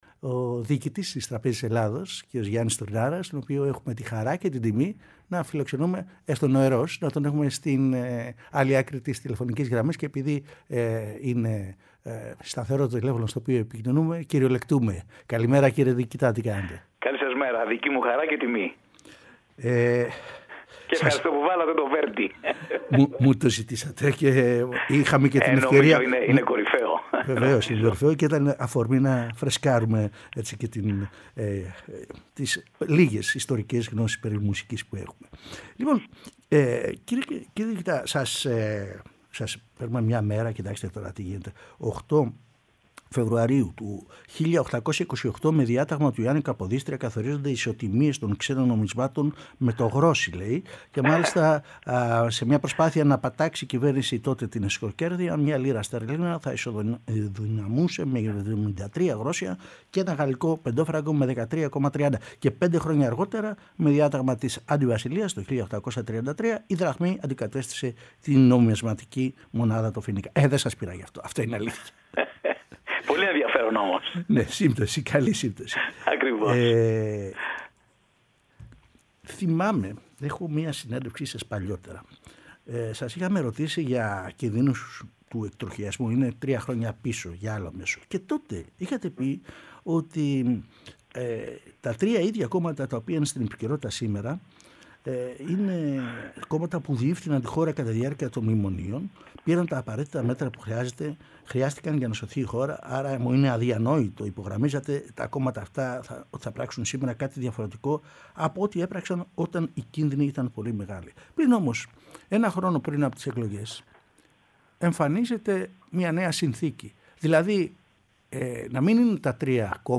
συνέντευξη εφ’όλης της ύλης